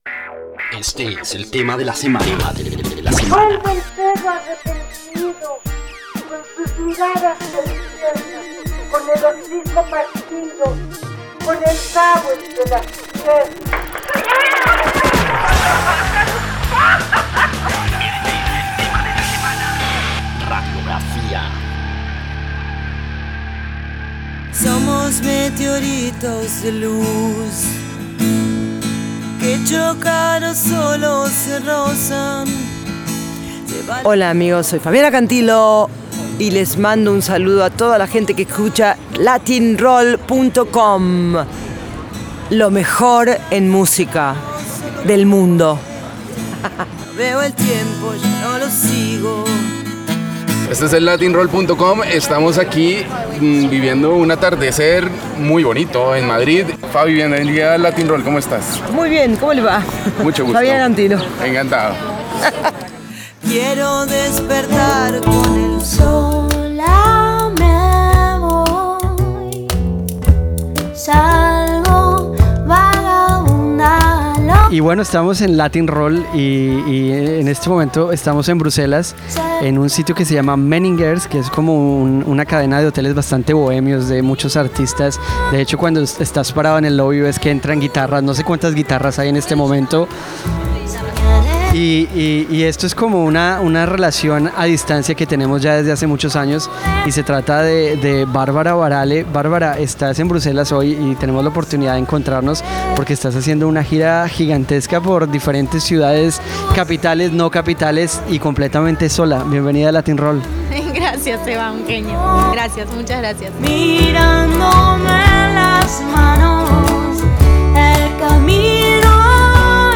Latin-Roll - Entrevistas